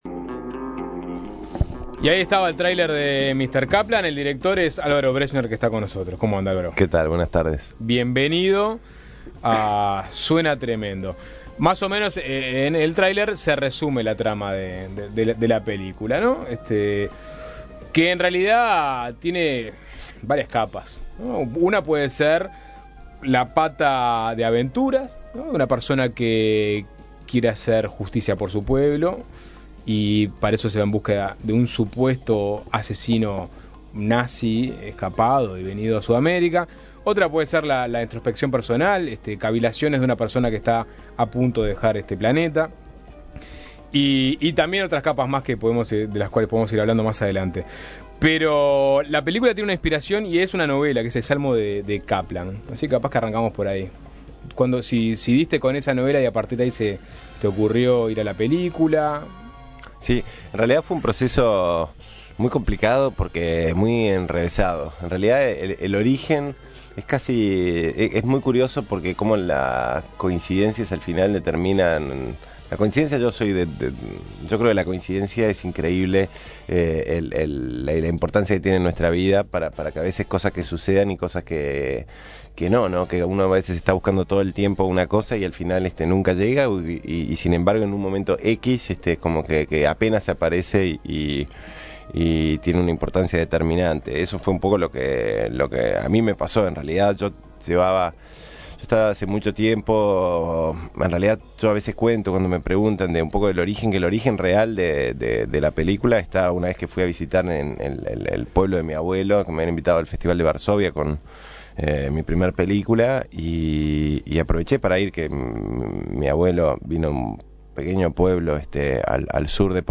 El autor de "Mal día para pescar", visitó Suena Tremendo para presentar su nueva película "Mr. Kaplan", estrenada el pasado 7 de agosto y aún en cartelera. El cineasta uruguayo advirtió que el cine lo apasiona y que sus películas le permitirán trascender en el tiempo.